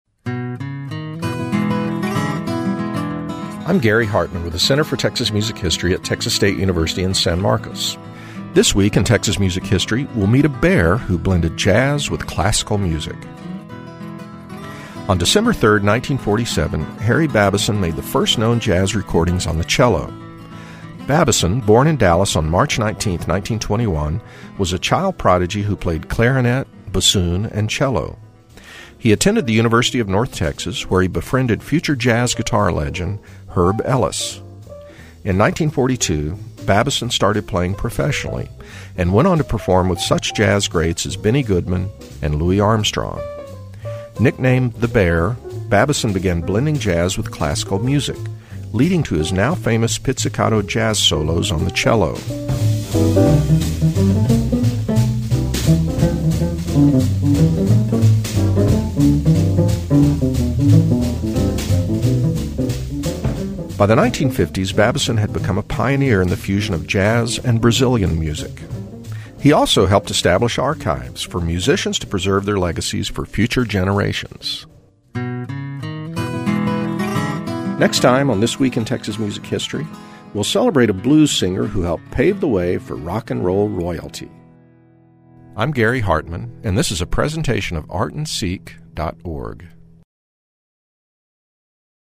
You can also hear This Week in Texas Music History on Friday on KXT and Saturday on KERA radio.